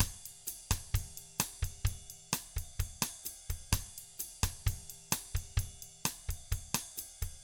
129BOSSAT3-L.wav